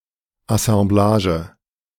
Assemblage (IPA: [asɑ̃ˈblaːʒə],[1]